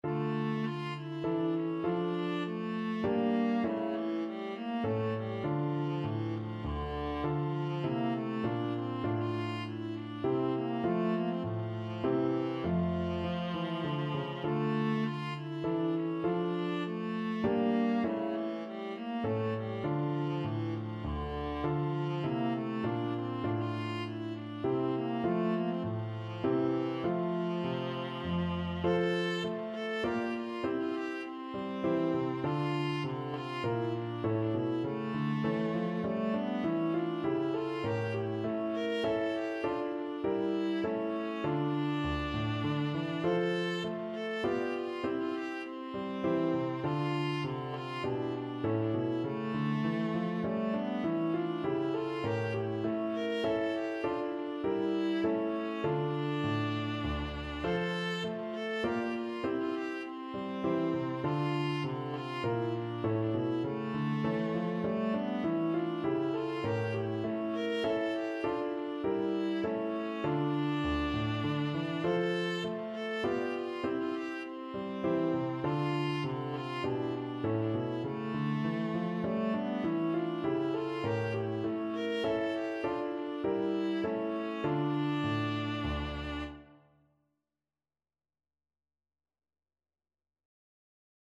Viola version
3/4 (View more 3/4 Music)
Classical (View more Classical Viola Music)